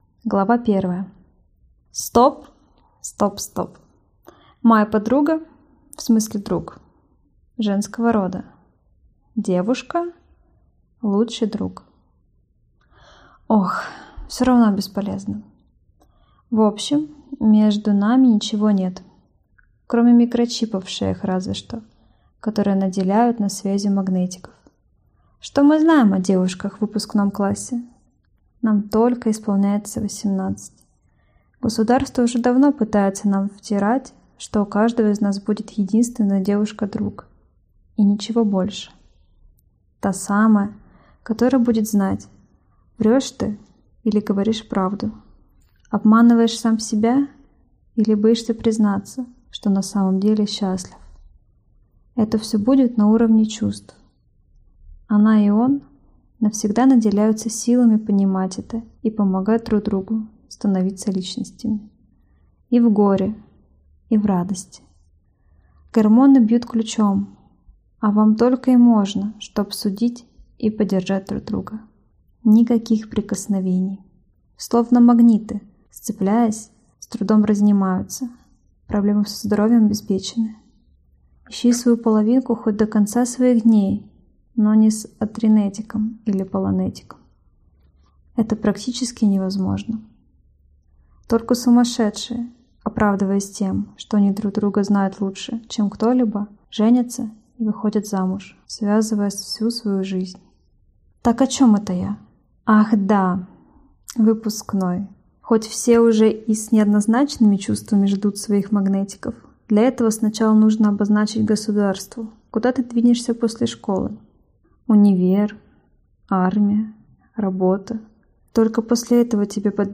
Аудиокнига Понимания ноль. Параллельно задавая вопрос | Библиотека аудиокниг